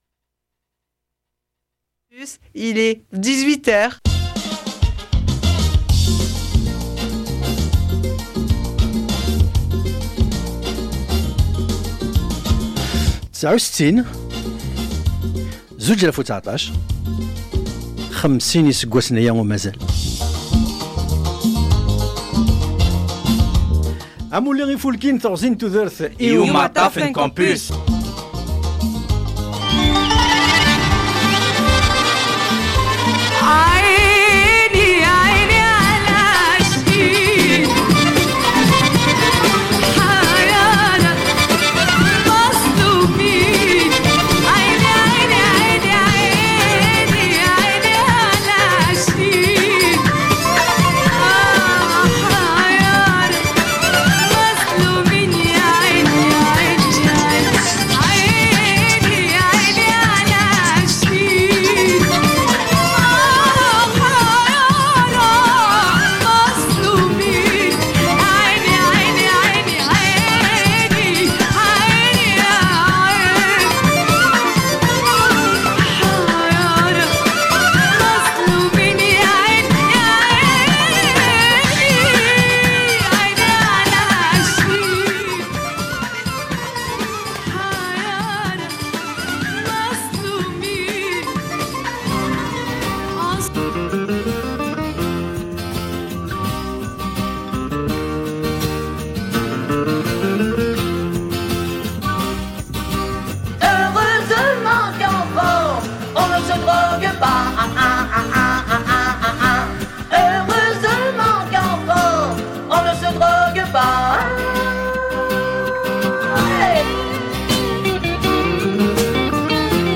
Au sommaire de cet épisode spécial diffusé le 14/07/2019 sur Radio Campus Lille : La liste des thèmes proposés et des chansons choisies :